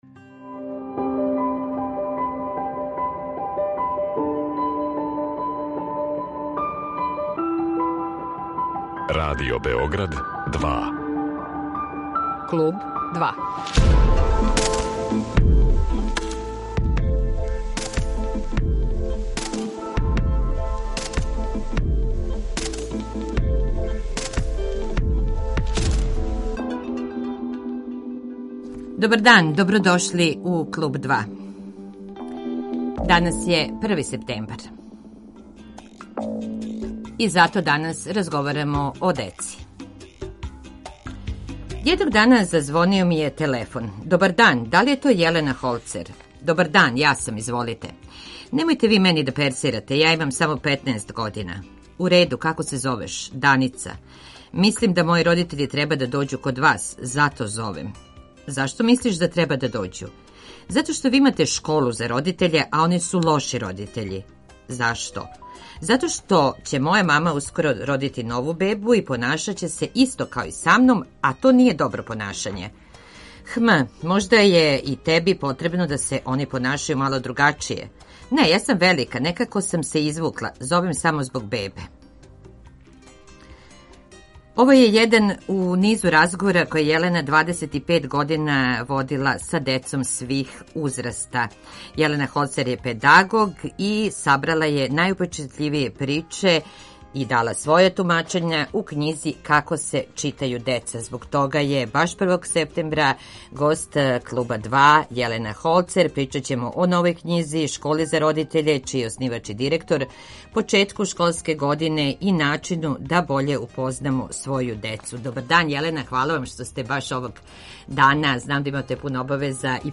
Гост емисије је педагог